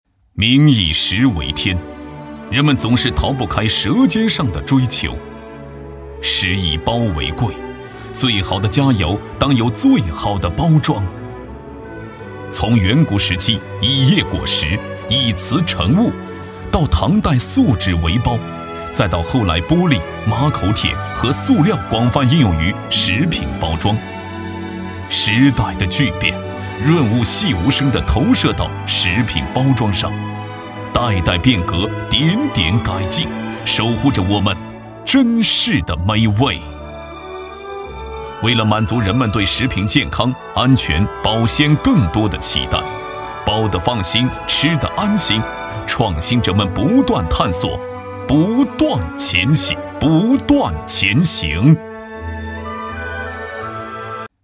男国421_专题_人物_人物专题.mp3